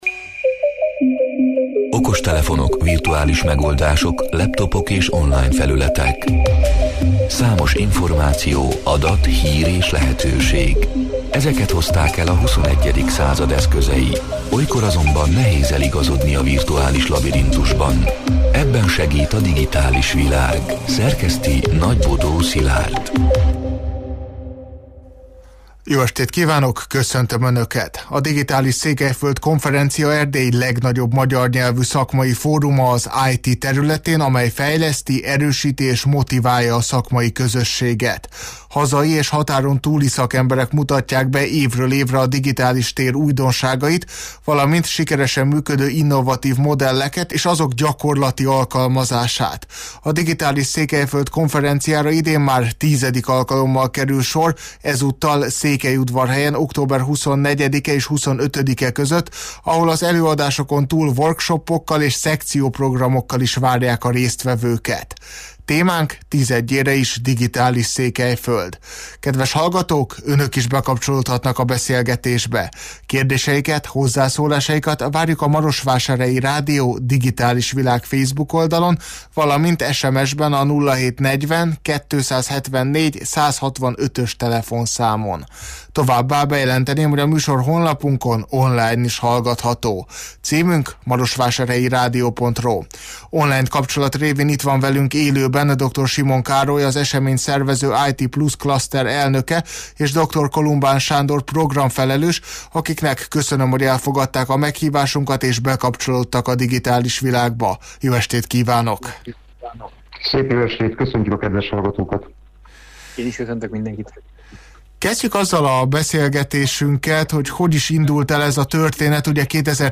A Marosvásárhelyi Rádió Digitális Világ (elhangzott: 2025. október 14-én, kedden este nyolc órától élőben) c. műsorának hanganyaga: